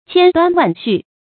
千端萬緒 注音： ㄑㄧㄢ ㄉㄨㄢ ㄨㄢˋ ㄒㄩˋ 讀音讀法： 意思解釋： 形容事情雜，頭緒多。